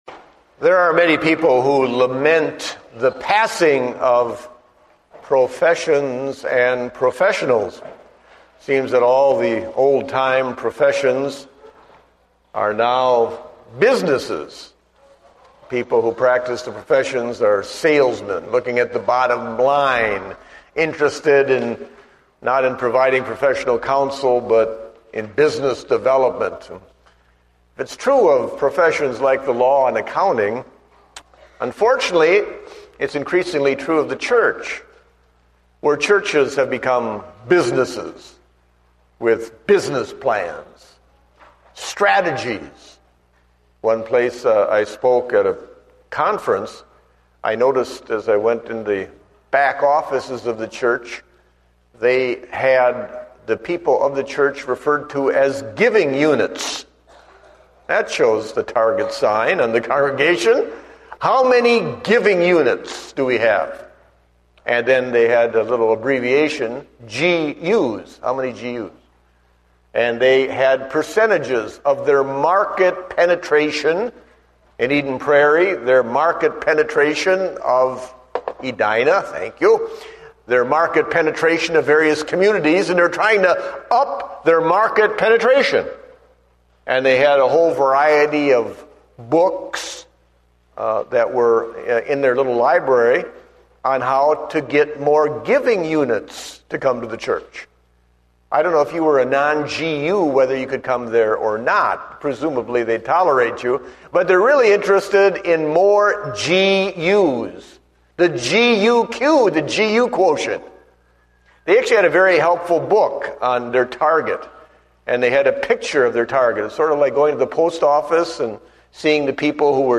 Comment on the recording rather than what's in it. Date: August 22, 2010 (Morning Service)